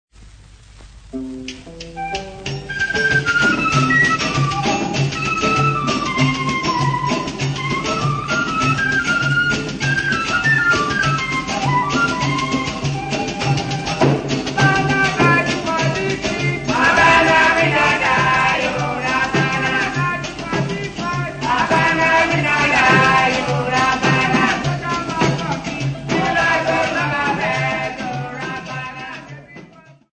Popular music--Africa
Dance music--Caribbean Area
field recordings